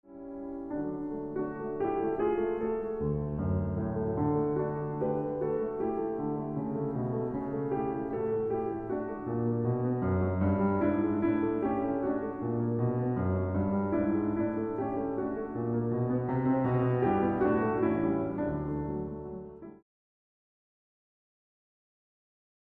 Ik genoot ook van het vaker voorkomende vraag-antwoordspel tussen de linkerhand en de rechterhand, zoals in het eerste stukje van de slotzin van het eerste deel: